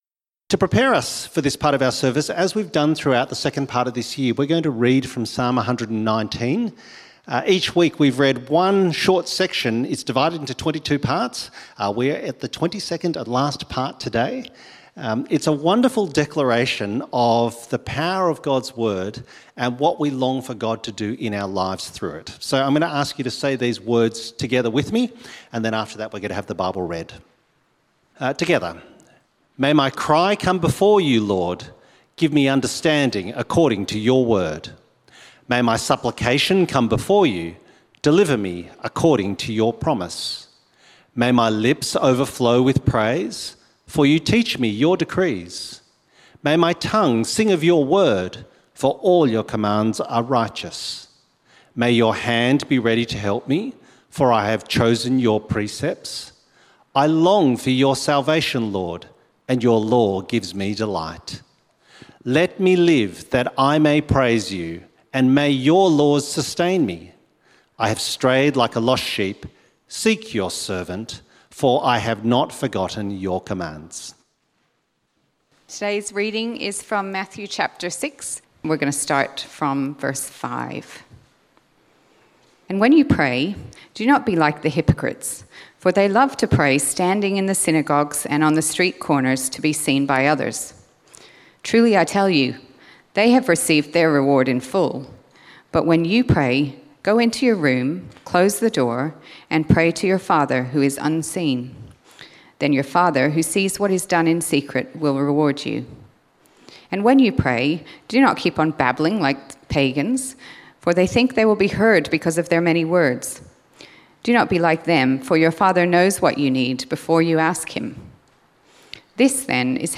Great Prayers in the Bible Sermon outline